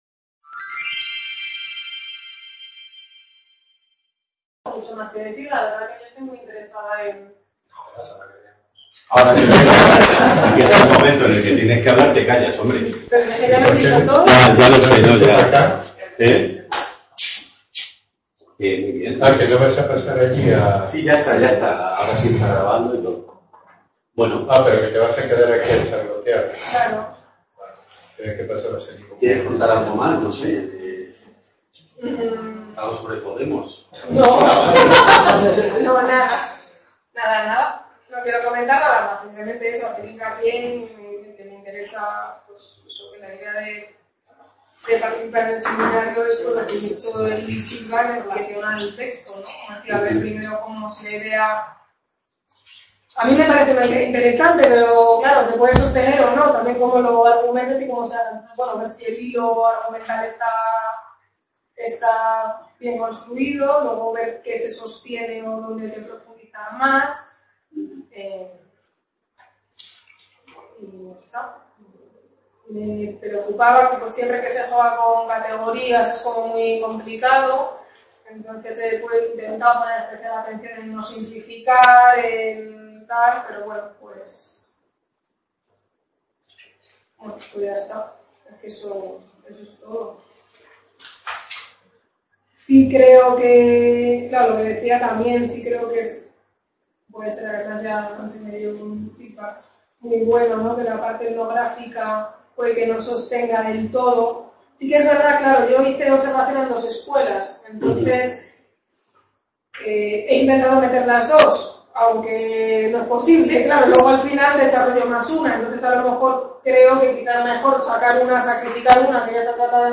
De indios y cholos. Imaginarios, racismo y escuela en Bolivia. Description Seminario Abierto del Departamento de Antropología.